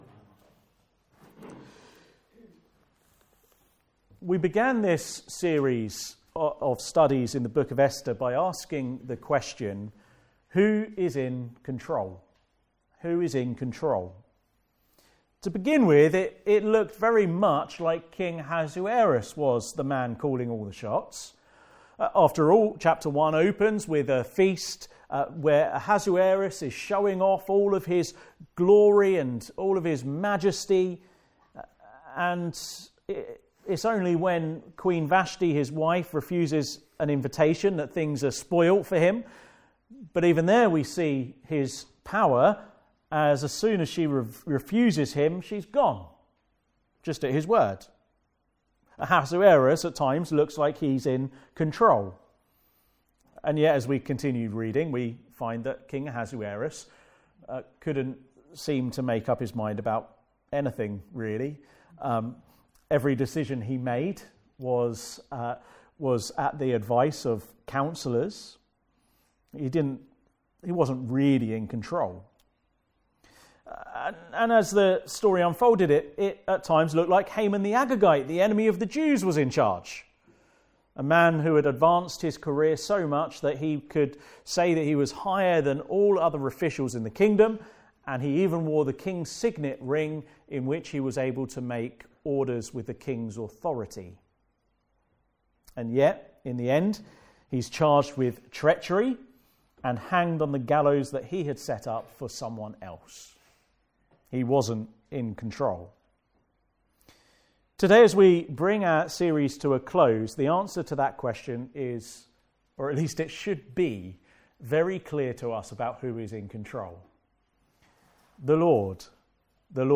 Passage: Esther 9:1-10:3 Service Type: Afternoon Service